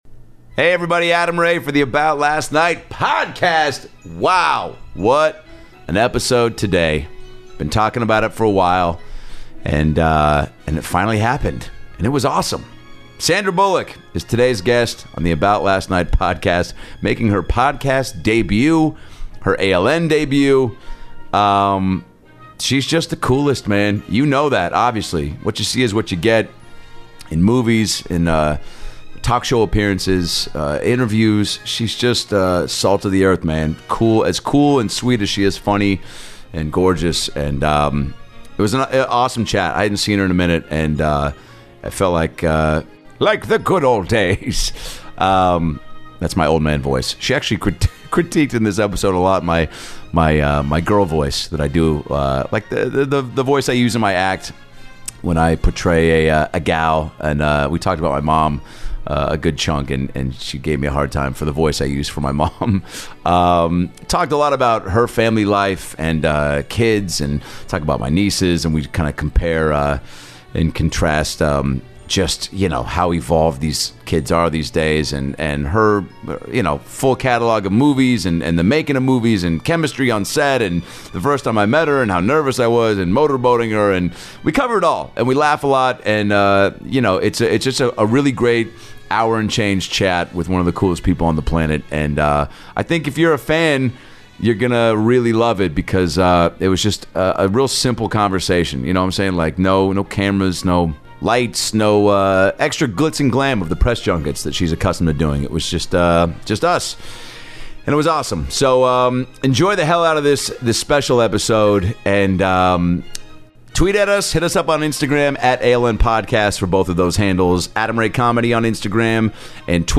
Sandra Bullock makes her podcast debut for an amazing one on one chat with Adam Ray! From prepping for her role in The Blind Side, to the worlds of motherhood, to why she won’t sing karaoke, to her naked scene with Ryan Reynolds, getting motor boated by Adam in The Heat, and takes some fan questions! It’s an incredibly fun & candid interview with one of the sweetest and most talented people on the planet!